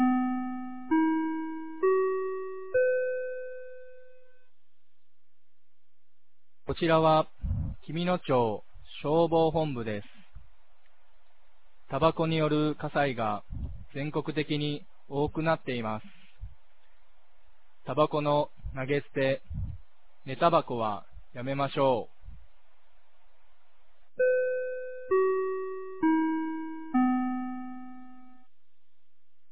2025年10月25日 16時00分に、紀美野町より全地区へ放送がありました。